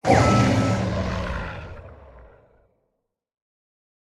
Minecraft Version Minecraft Version latest Latest Release | Latest Snapshot latest / assets / minecraft / sounds / mob / warden / death_2.ogg Compare With Compare With Latest Release | Latest Snapshot
death_2.ogg